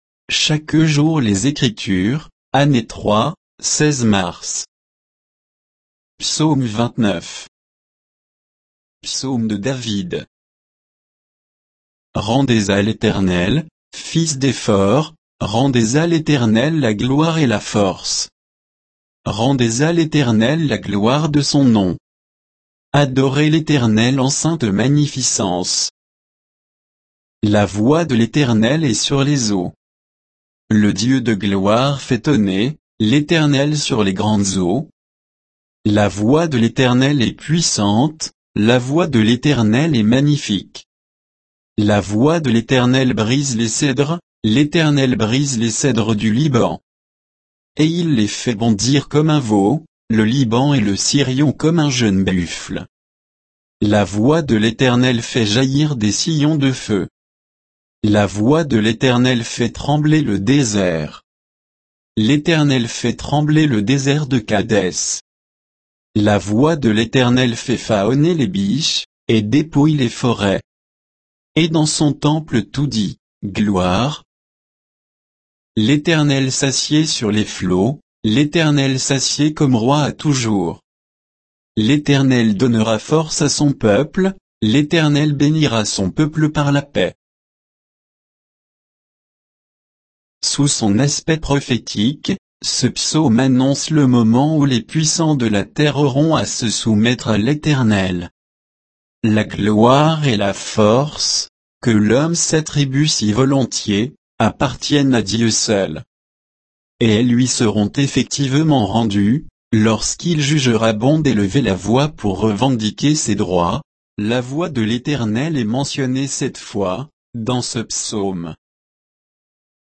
Méditation quoditienne de Chaque jour les Écritures sur Psaume 29